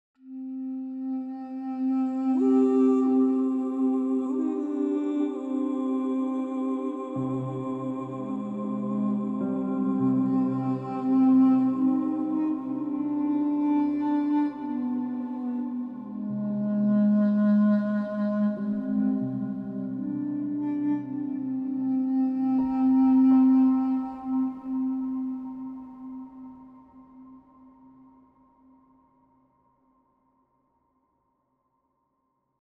duduk.mp3